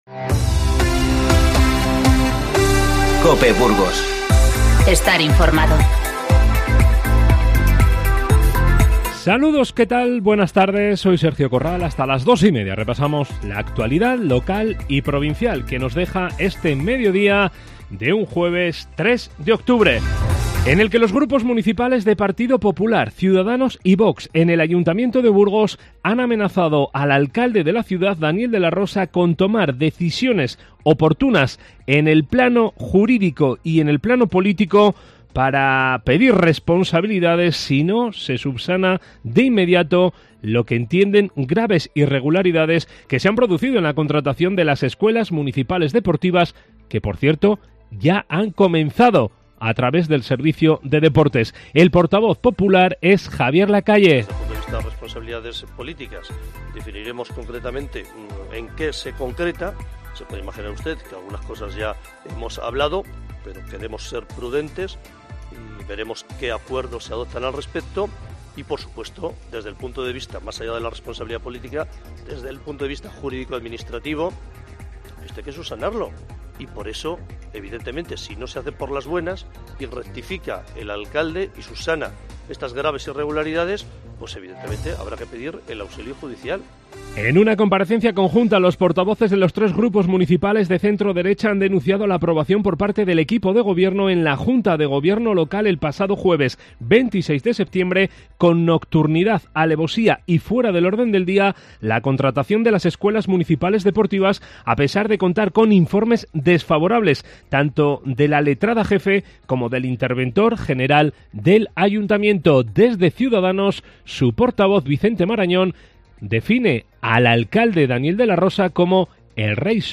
INFORMATIVO Mediodía 3-9-19.